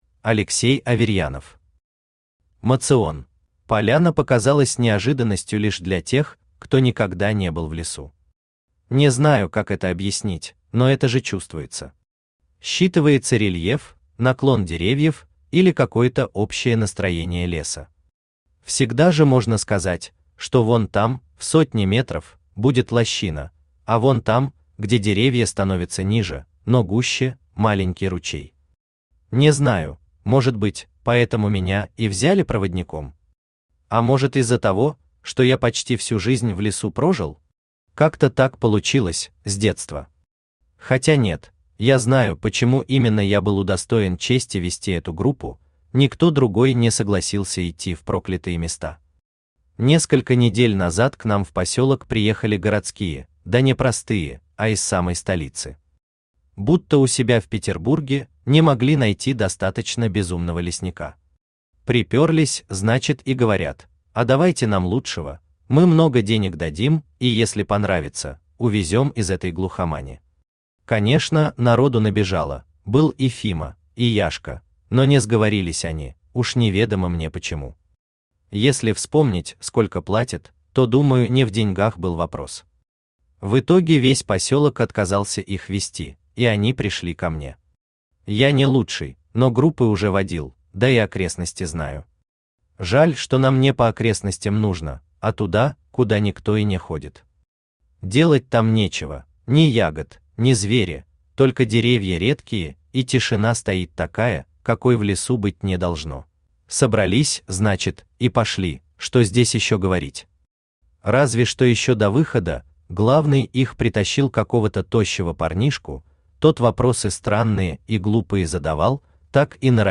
Aудиокнига Моцион Автор Алексей Аверьянов Читает аудиокнигу Авточтец ЛитРес.